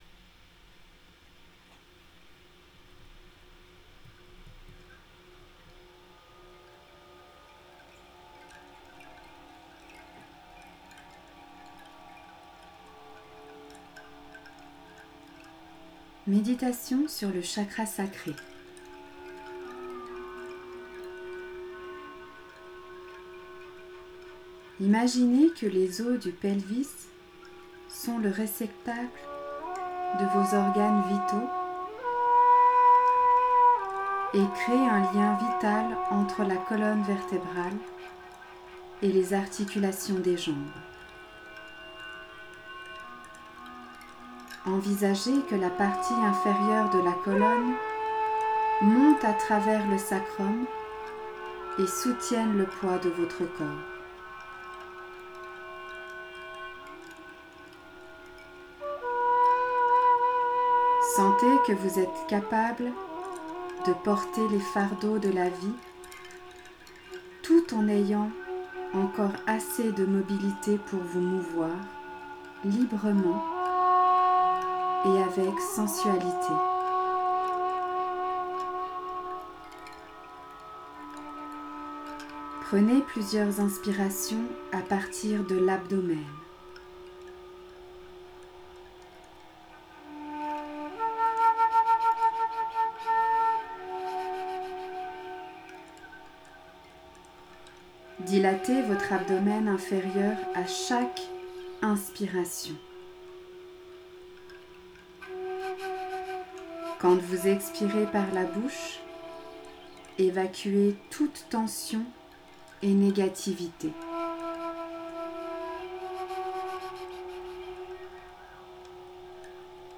Méditation - chakra sacré
meditation-chakra-sacre-avril2021.mp3